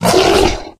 255081e1ee Divergent / mods / Soundscape Overhaul / gamedata / sounds / monsters / bloodsucker / hit_2.ogg 8.4 KiB (Stored with Git LFS) Raw History